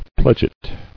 [pled·get]